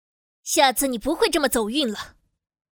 国语少年素人 、女动漫动画游戏影视 、看稿报价女游11 国语 女声 游戏 王者荣耀角色模仿-21芈月 素人